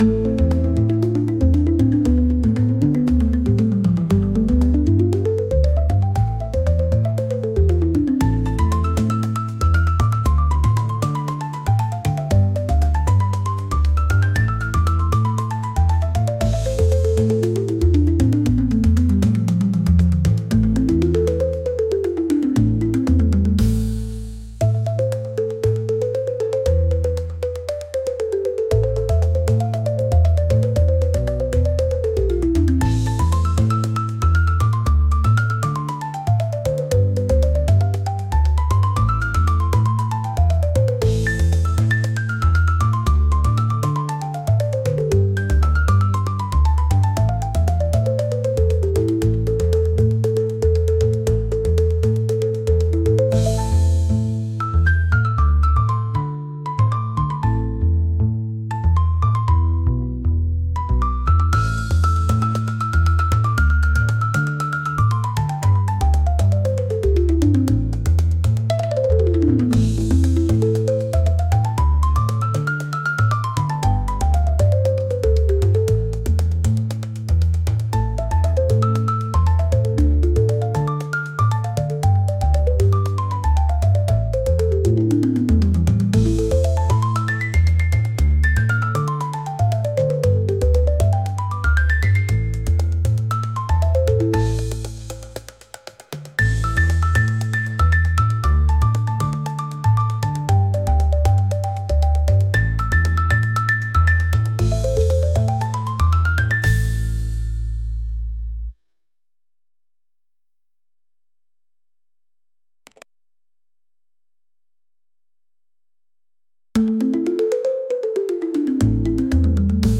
world | rhythmic